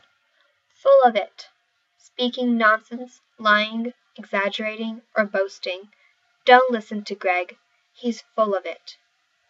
英語ネイティブによる発音は以下をクリックしてください。